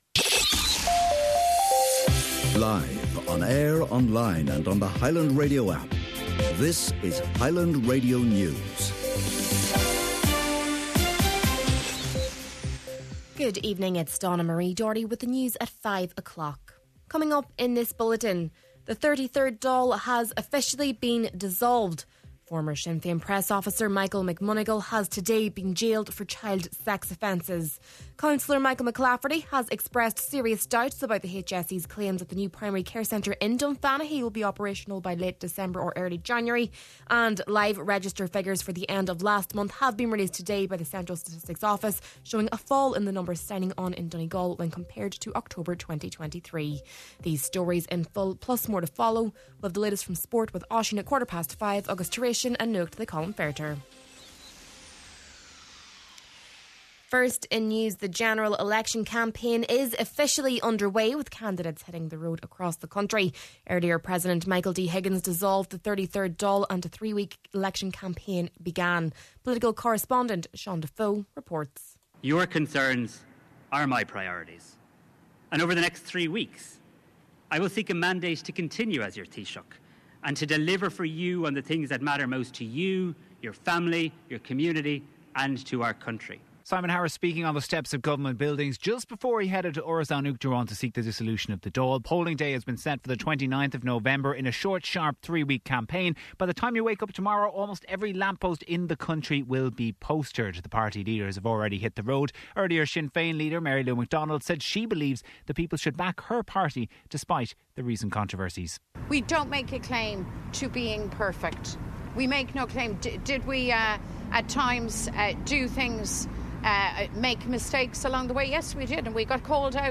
Main Evening News, Sport, An Nuacht and Obituary Notices – Friday, November 8th